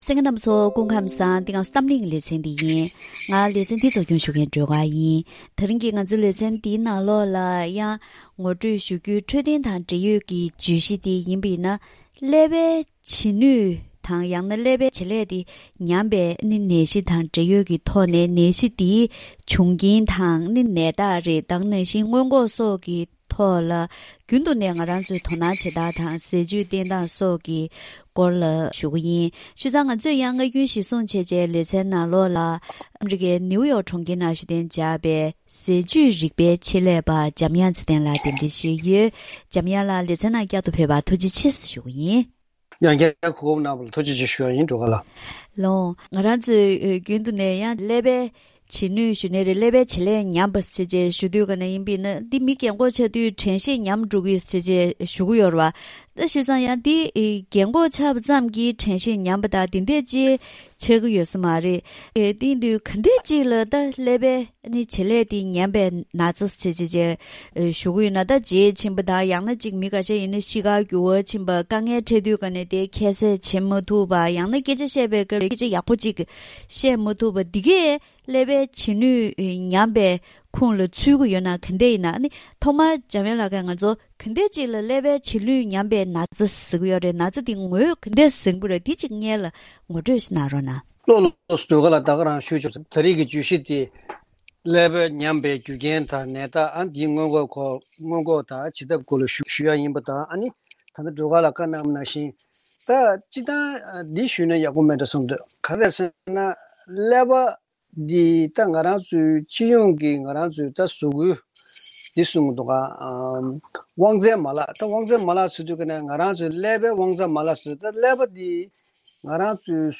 ད་རིང་གི་གཏམ་གླེང་ལེ་ཚན་ནང་ཀླད་པའི་བྱེད་ནུས་ཉམས་པའི་ནད་གཞི་དང་འབྲེལ་ཡོད་ཀྱི་ཐོག་ནས་ནད་གཞི་འདིའི་བྱུང་རྐྱེན་དང་ནད་རྟགས།